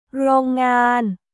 โรงงาน　ローンガーン